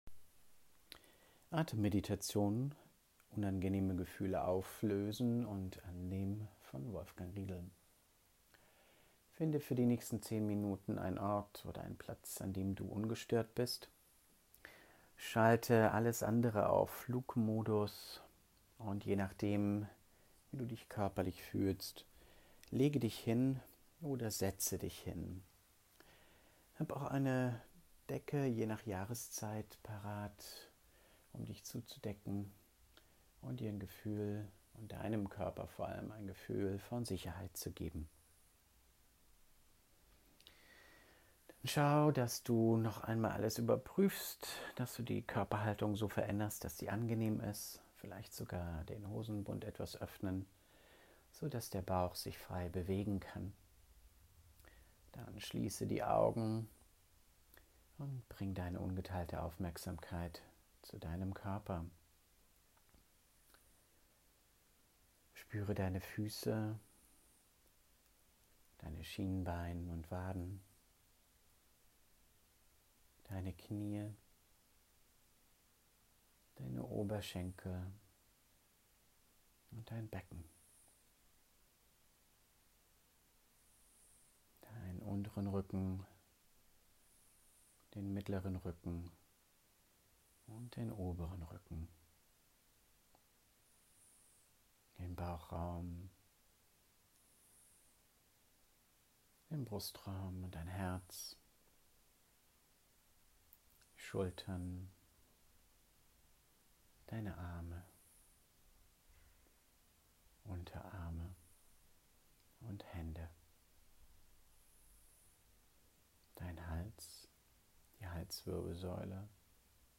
Atem-Meditation-unangenehme-Gefühle-annehmen-auflösen-.mp3.mp3